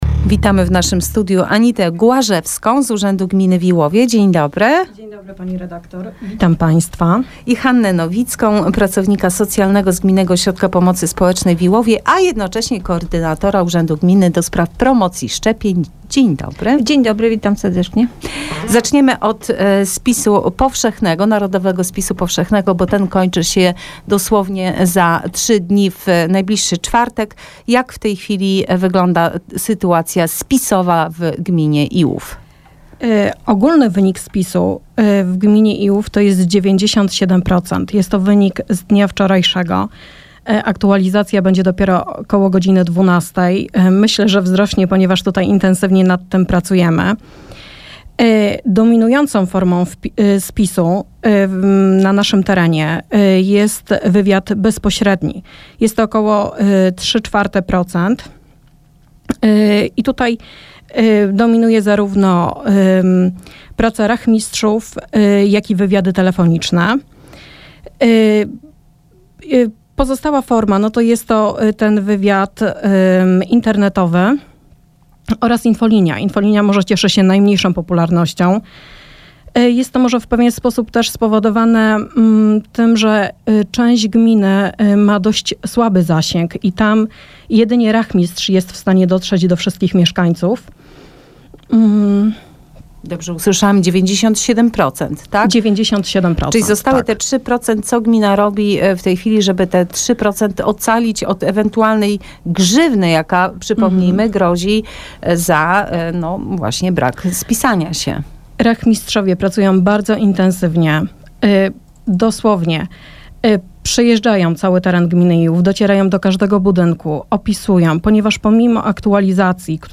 Wywiad w Radio Sochaczew - Najnowsze - Gmina Iłów